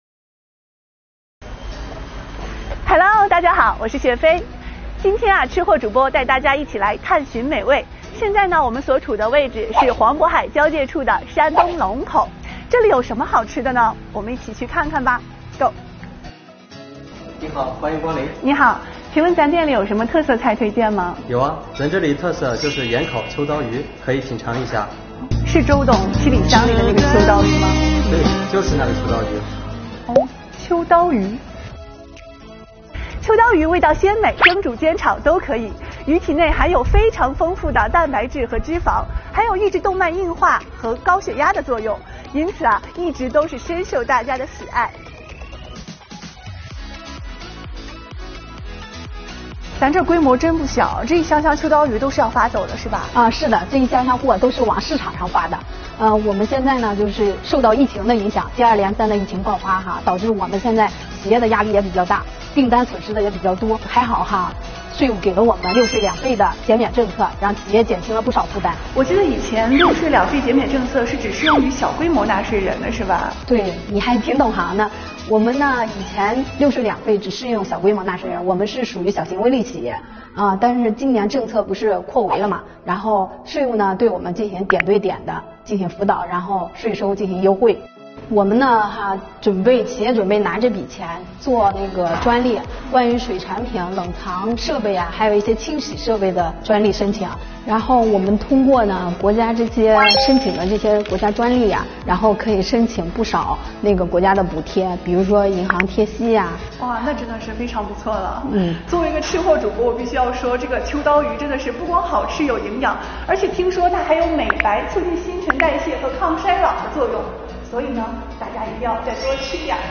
提起秋刀鱼，除了会想到美食，周董的“七里香”，还有什么和它有关呢，赶紧跟随“吃货主播”去一探究竟吧！
作品通过轻松活泼的Vlog形式，以记者的视角聚焦食品加工冷藏出口企业现状。多项税费优惠政策不仅为中小微企业和个体工商户纾困解难，更提振了市场主体信心。